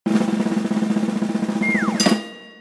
Egg chop
13-sfx-egg-chop-mp3cut.mp3